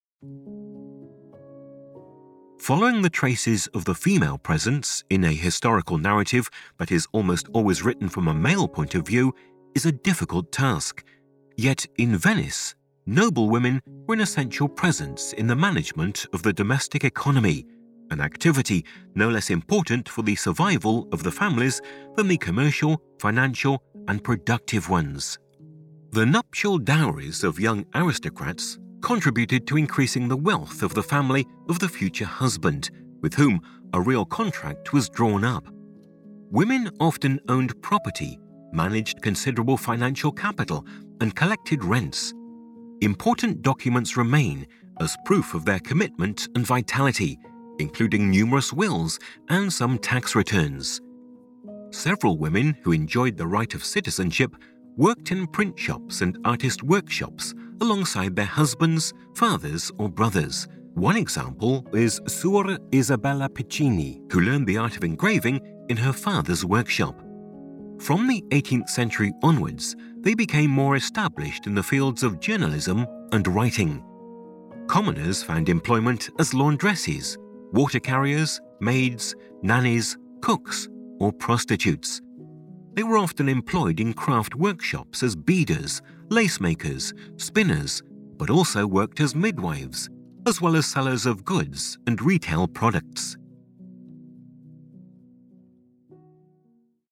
Le nostre tracce audio sono prodotte da professionisti del settore cinematografico: attori madrelingua e compositori professionisti danno vita a una colonna sonora originale per raccontare il tuo museo.